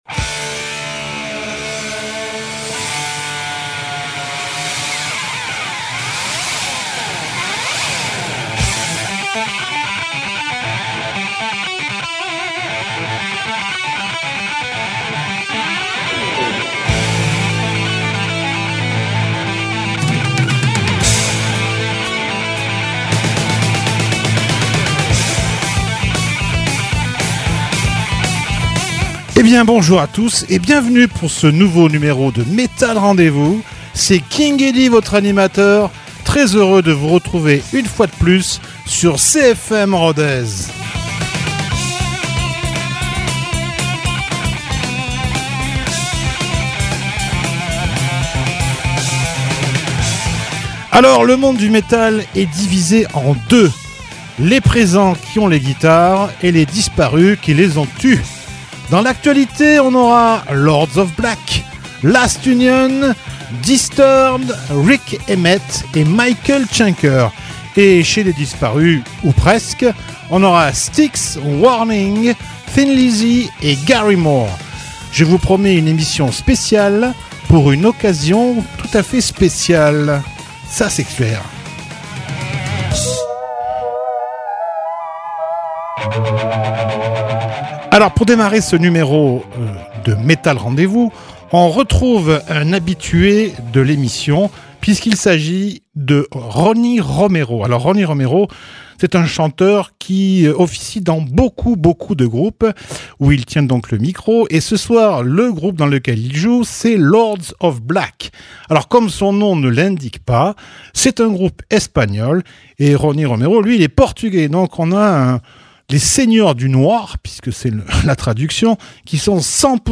Metal rendez-vous